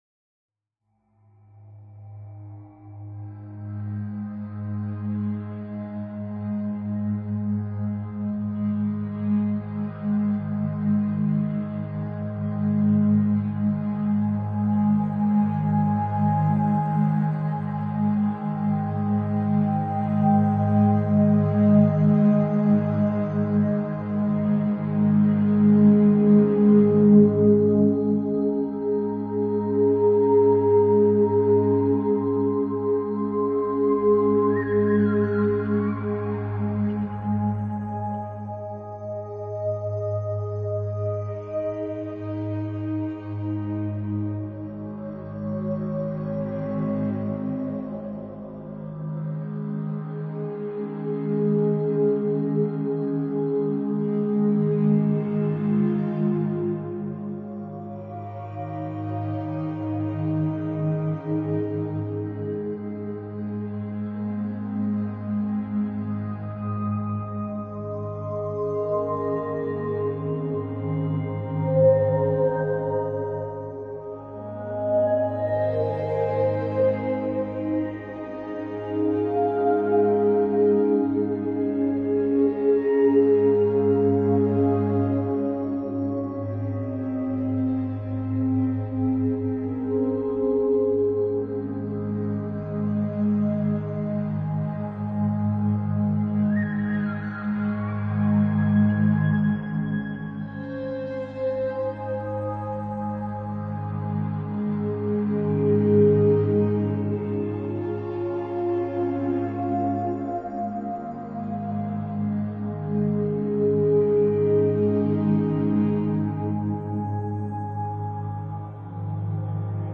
戏剧性的，梦幻般的，宽敞的。
催眠具有一种奇怪的意识和认知感。 一种漂浮在空中或迷失自我、漂浮的感觉。